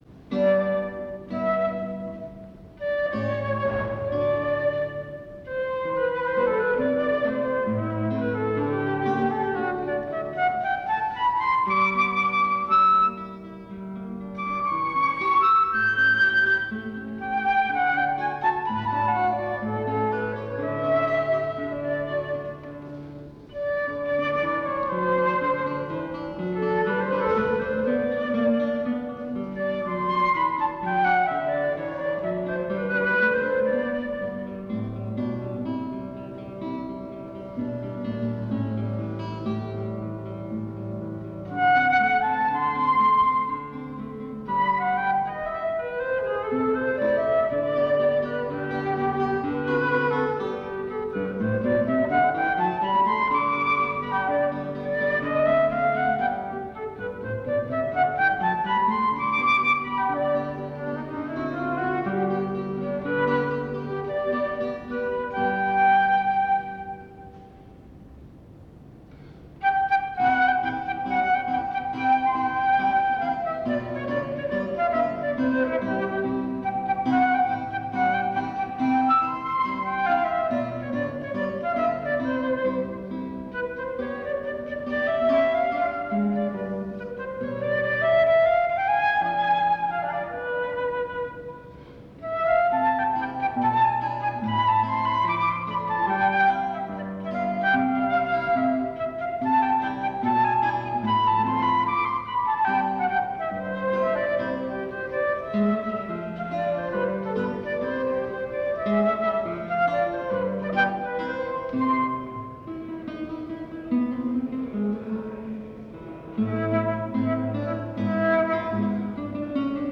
Duo flauto e chitarra
flauto
chitarra
Circolo Eridano, Torino 2 Aprile 1993